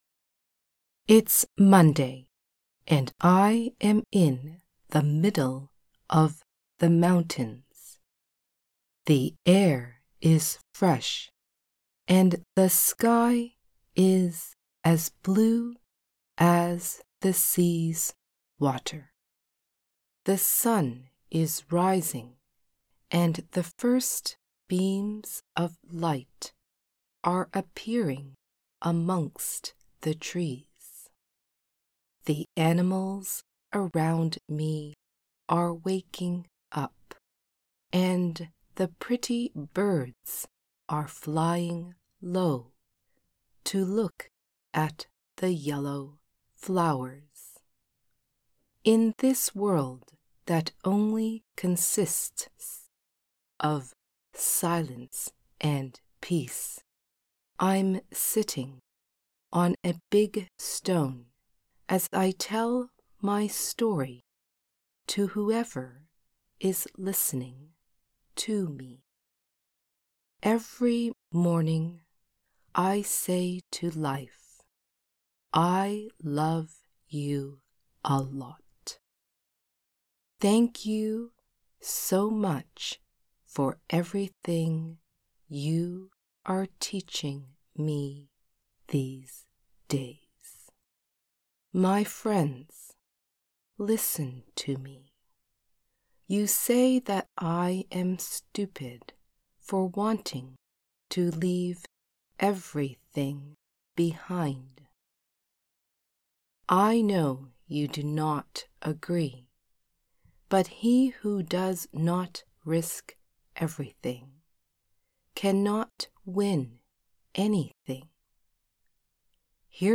Langsam
Part 1 slow (female).mp3